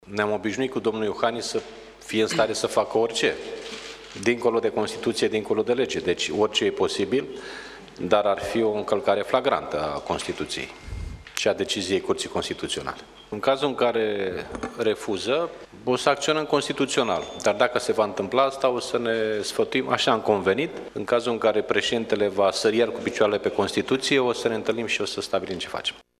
Președintele PSD, Liviu Dragnea a declarant că dacă șeful statului va respinge și aceste nominalizări, ar fi o încălcare gravă a Constituției: